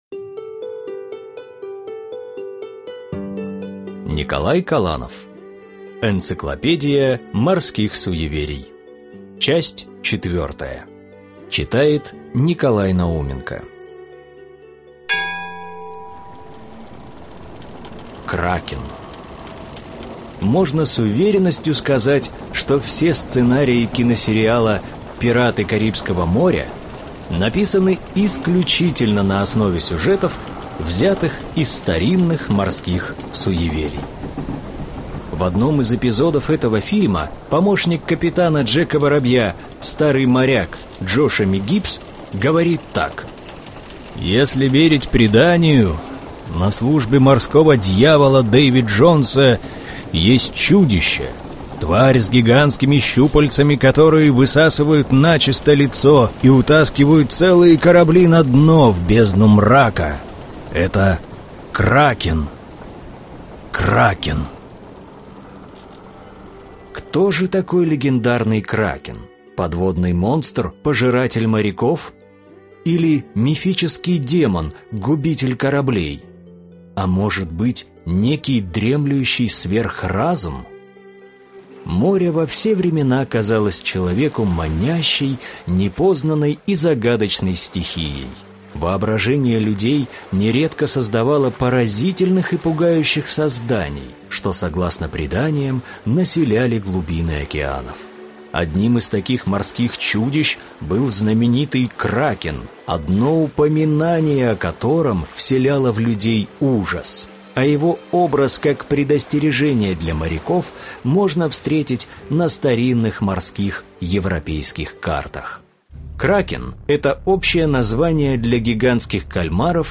Аудиокнига Энциклопедия морских суеверий. Часть 4 | Библиотека аудиокниг